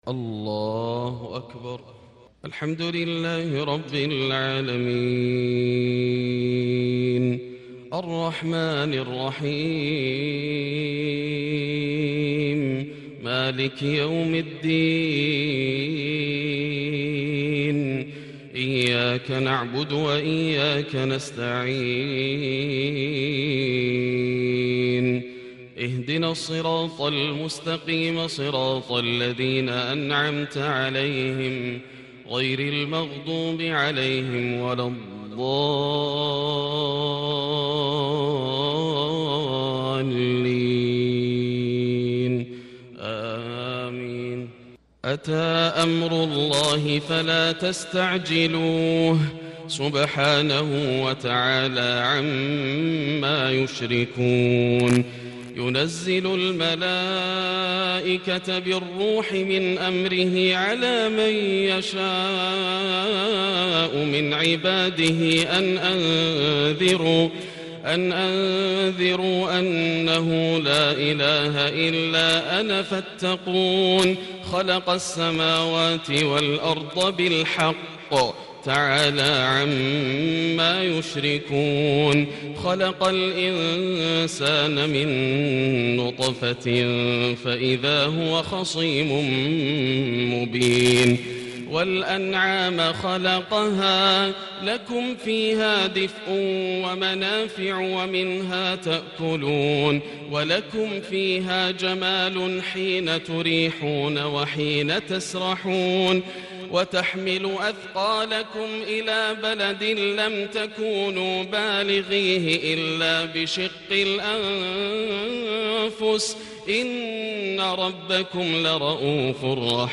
فجرية خاشعة ومؤثرة من سورة النحل-فجر الاحد 1-6-1441 > عام 1441 > الفروض - تلاوات ياسر الدوسري